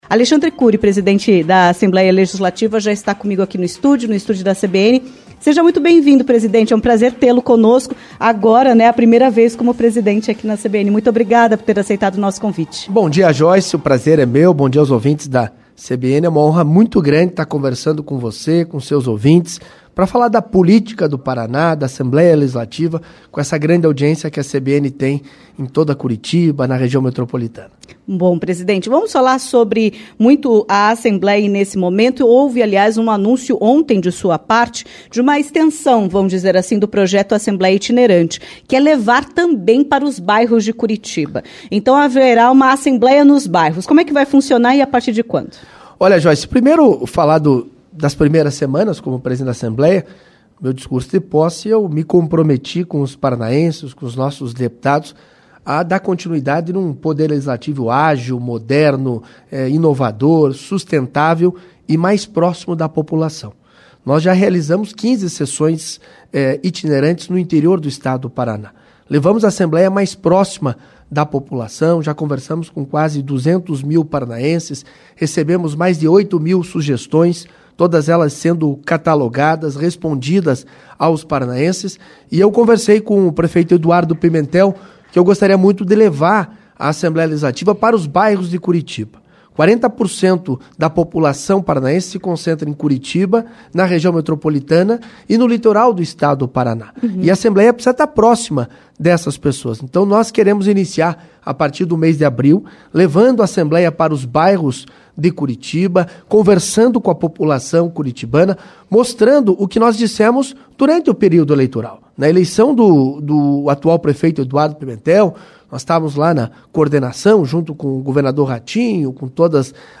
Em entrevista à CBN Curitiba, nesta terça-feira (18), o presidente da Assembleia Legislativa do Paraná, Alexandre Curi, revelou interesse na disputa pelo governo do estado em 2026.
ENTREVISTA-ALEXANDRE-CURI-1802.mp3